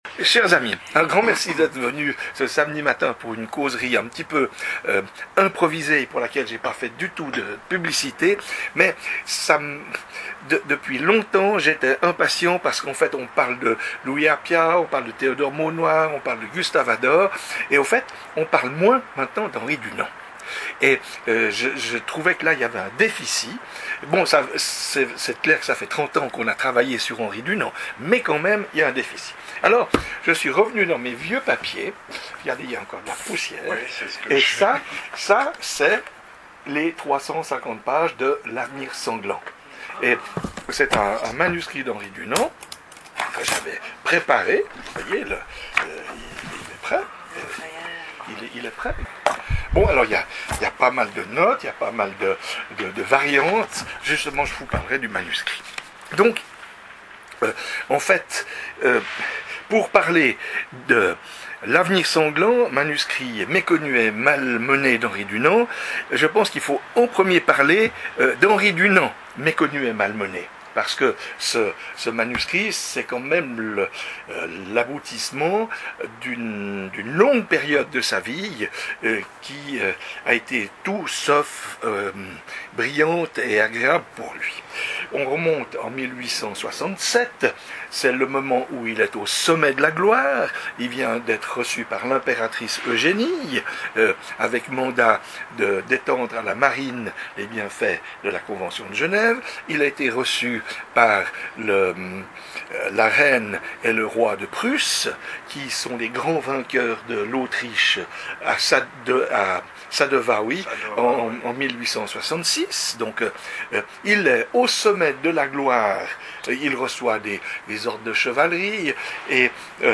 Communication pour rafra�chir les recherches men�es il y a des lustres et pour mettre en �vidence une publication majeure d'Henry Dunant
Enregistrement r�alis� le samedi 11 septembre 2021 au Centre Henry Dunant